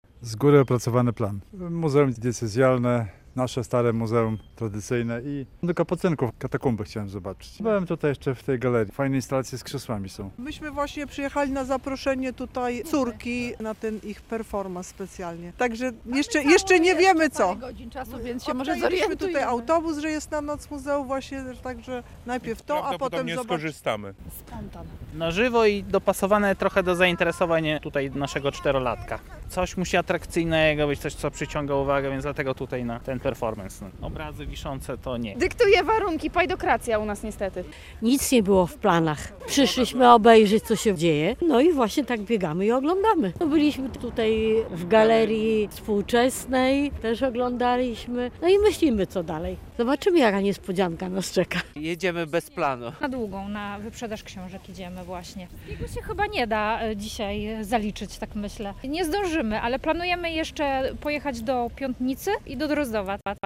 Noc Muzeów w Łomży - relacja